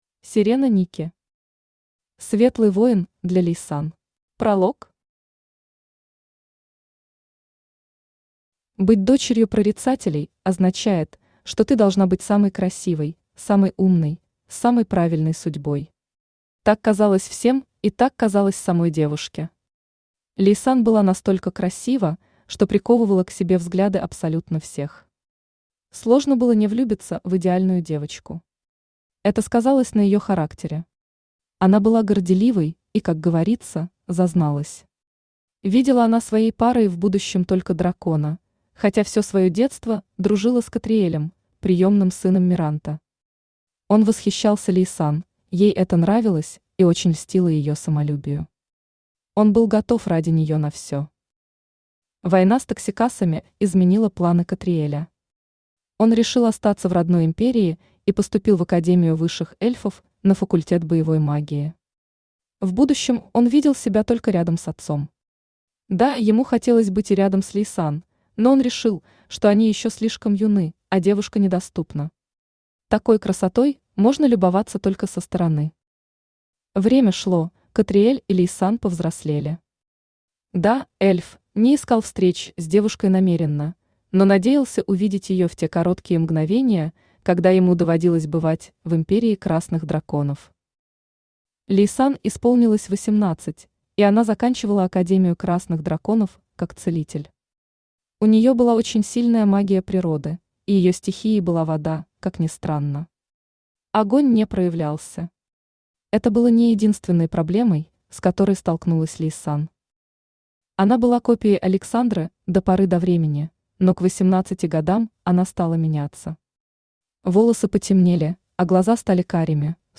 Аудиокнига Светлый воин для Ляйсан | Библиотека аудиокниг
Aудиокнига Светлый воин для Ляйсан Автор Серена Никки Читает аудиокнигу Авточтец ЛитРес.